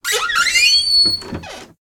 door.ogg